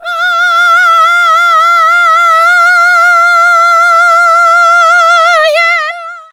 SCREAM 7.wav